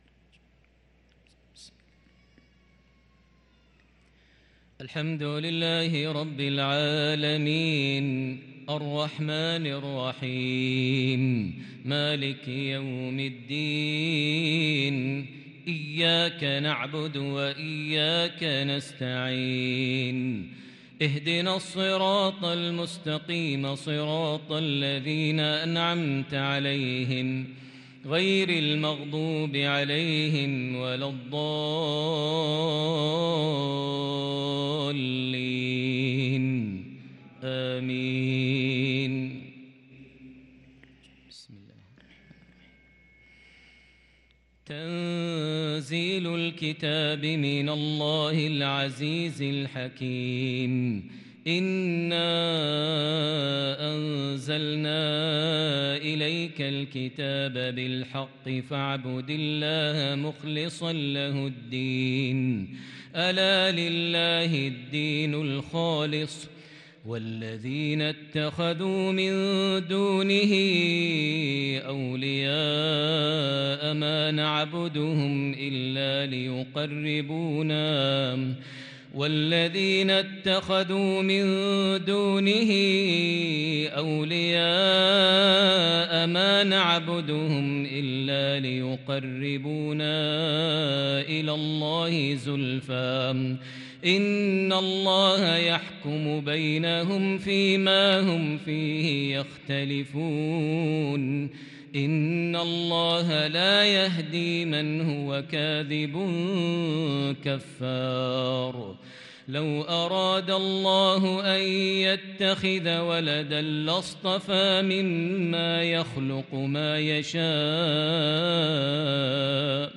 صلاة العشاء للقارئ ماهر المعيقلي 25 جمادي الأول 1444 هـ
تِلَاوَات الْحَرَمَيْن .